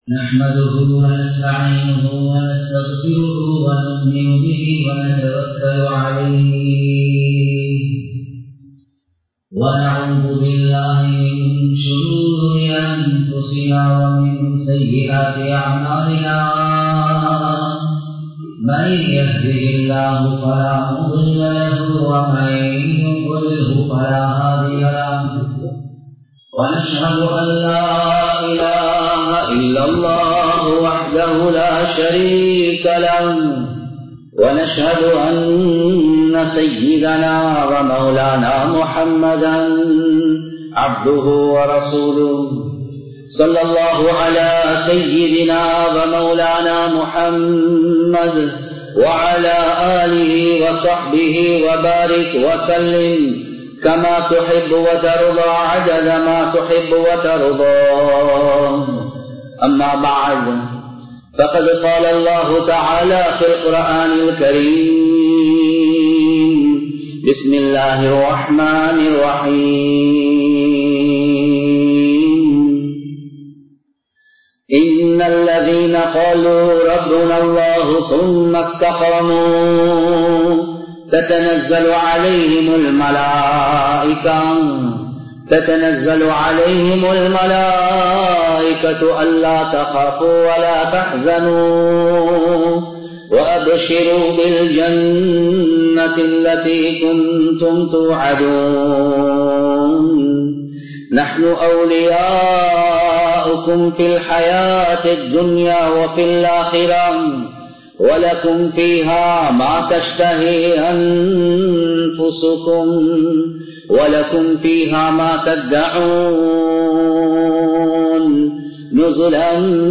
இஸ்லாம் கூறும் பொருளாதாரம் | Audio Bayans | All Ceylon Muslim Youth Community | Addalaichenai